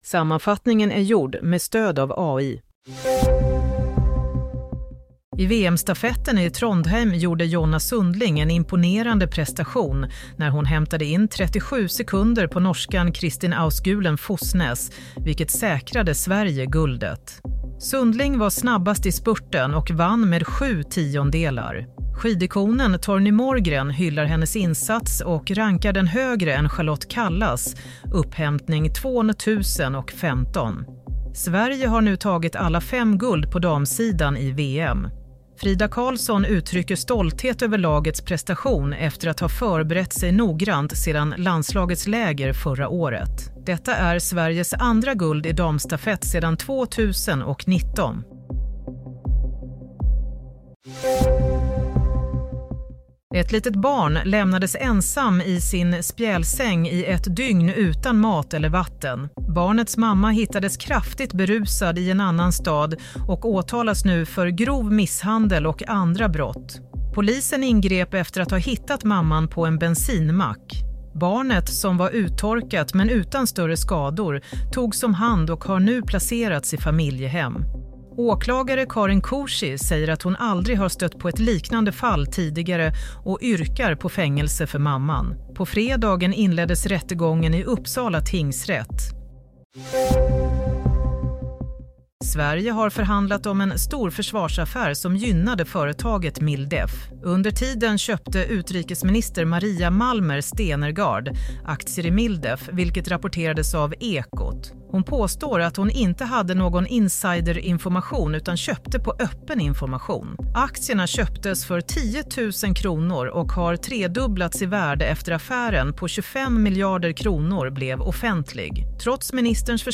Nyhetssammanfattning – 7 mars 16:00